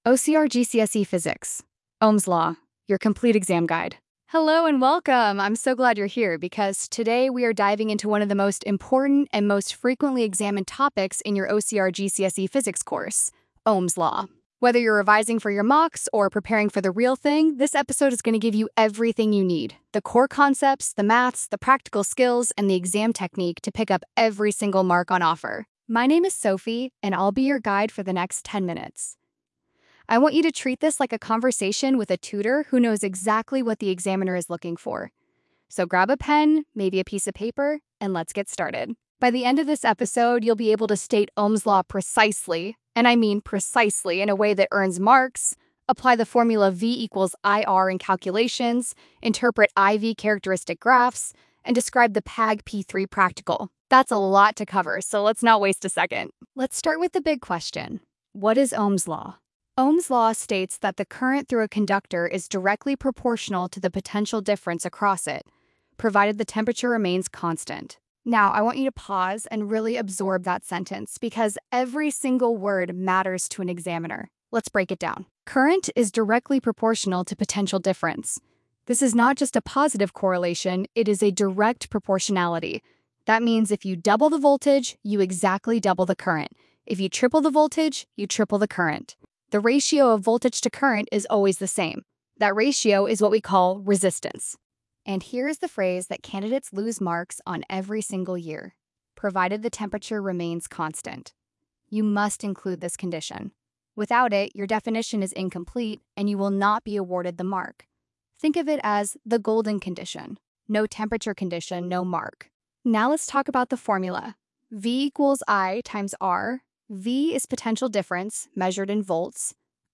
Listen to this 10-minute audio guide for a complete revision of the Ohm's Law topic, including exam tips and a quick-fire quiz.